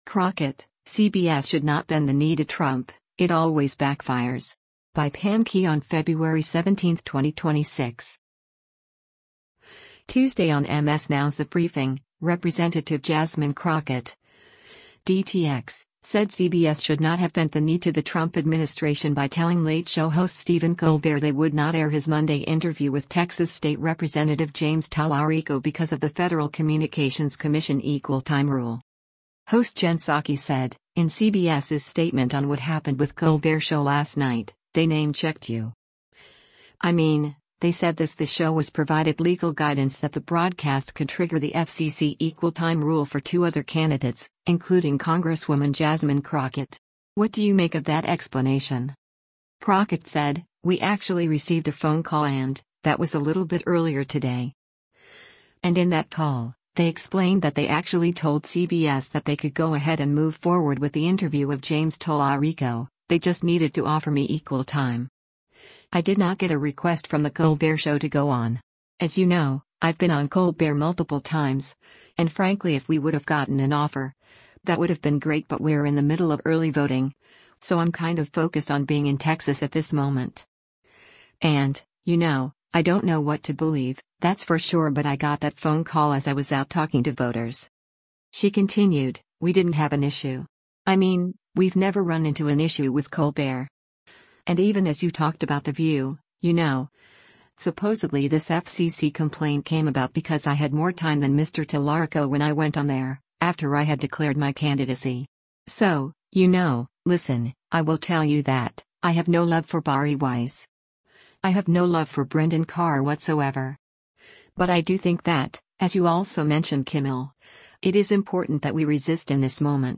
Tuesday on MS NOW’s “The Briefing,” Rep. Jasmine Crockett (D-TX) said CBS should not have bent the knee to the Trump administration by telling “Late Show” host Stephen Colbert they would not air his Monday interview with Texas State Rep. James Talarico because of the Federal Communications Commission equal time rule.